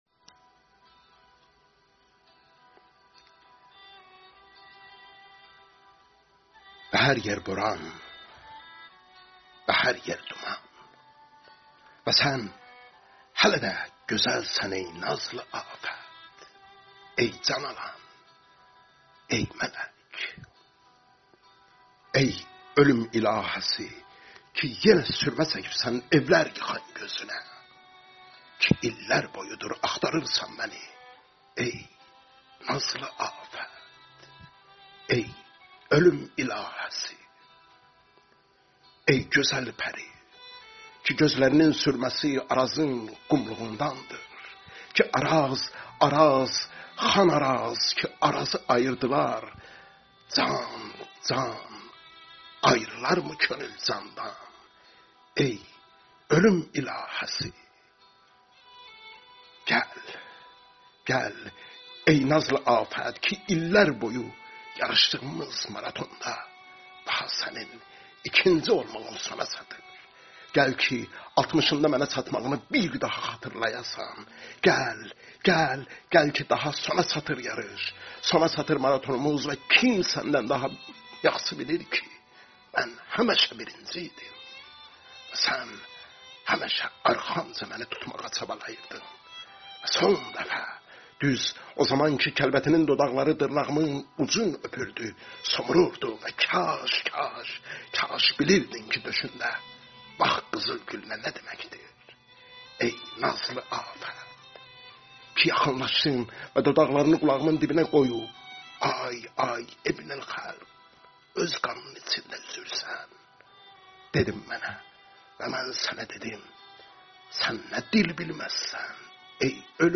• شعر